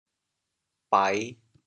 How to say the words 排 in Teochew？
bai5.mp3